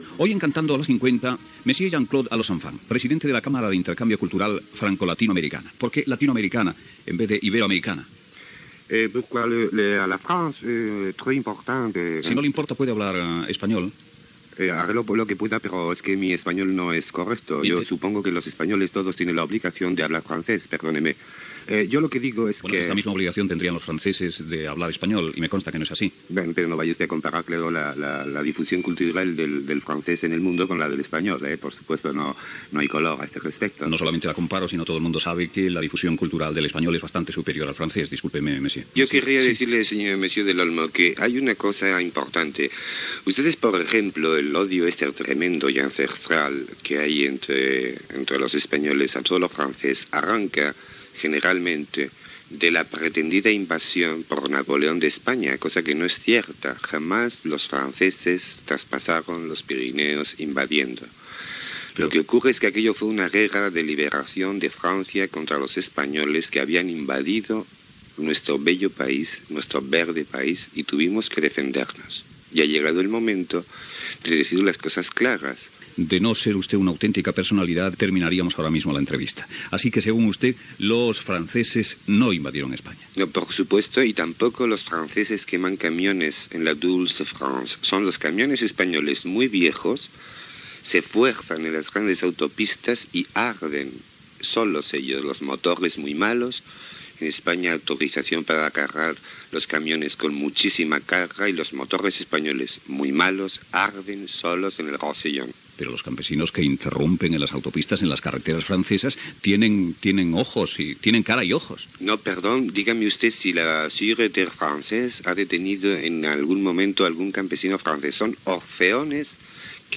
Entrevista fictícia a la secció "Cantando las 50", el dia dels innocents. Els idiomes francès i espanyol, la Guerra França Espanya i la crema de camions a la frontera de La Jonquera
Info-entreteniment